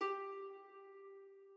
harp1_2.ogg